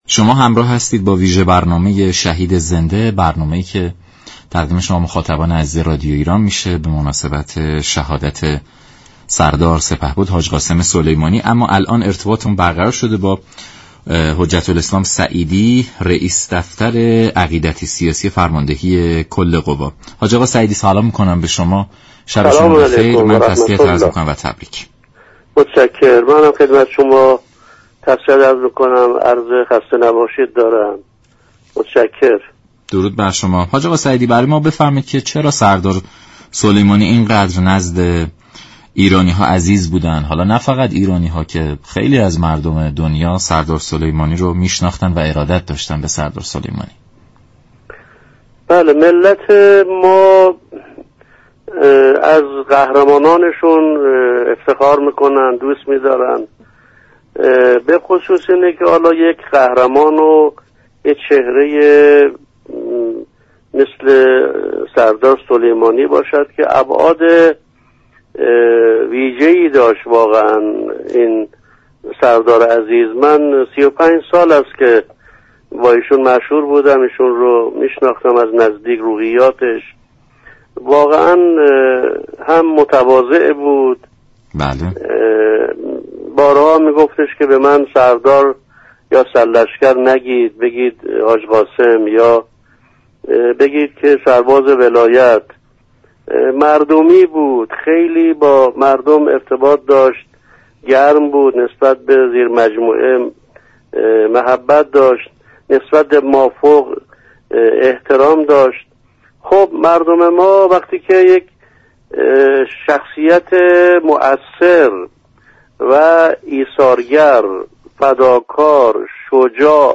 رئیس دفتر عقیدتی سیاسی فرماندهی معظم كل قوا در گفت و گو با رادیو ایران گفت: یكی از اقدامات مهم سردار سلیمانی طی سال های اخیر، ایجاد امنیت برای ایران و ملت بوده است.